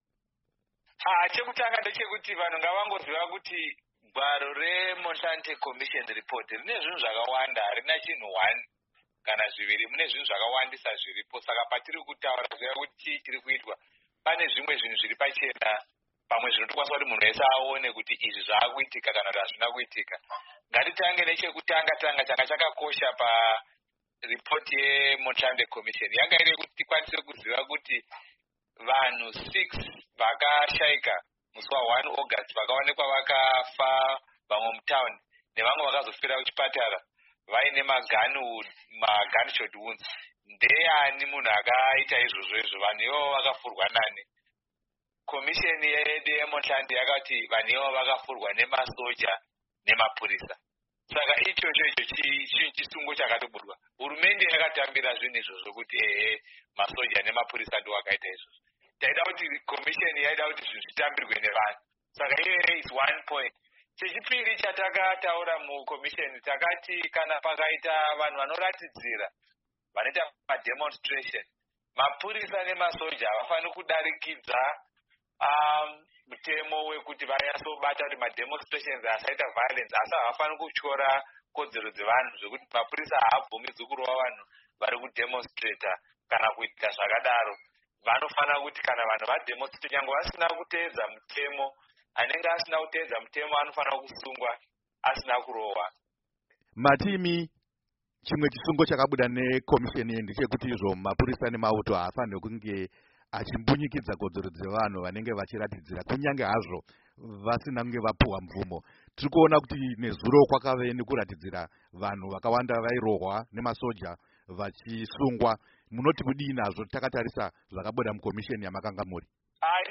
Hurukuro naVaLovemore Madhuku